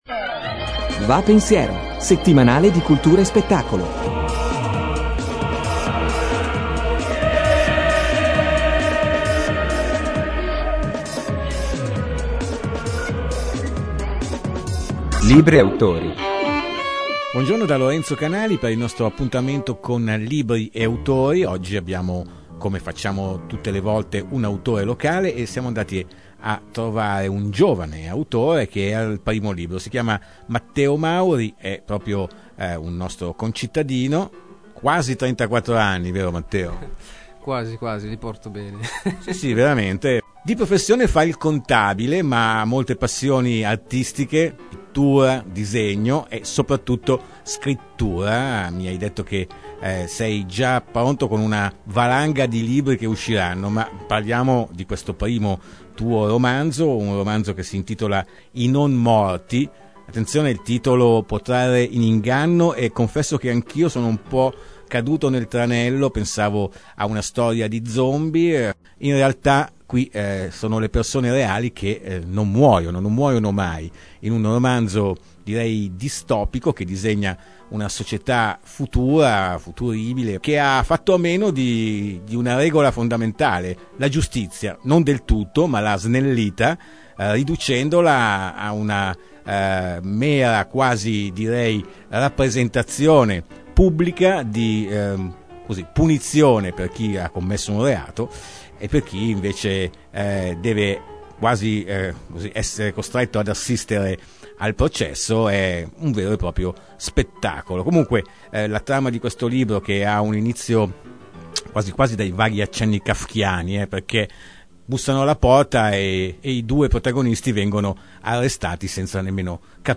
Intervista autore a radio